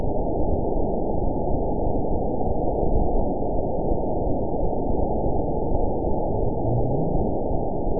event 921841 date 12/19/24 time 22:19:50 GMT (4 months, 2 weeks ago) score 8.97 location TSS-AB02 detected by nrw target species NRW annotations +NRW Spectrogram: Frequency (kHz) vs. Time (s) audio not available .wav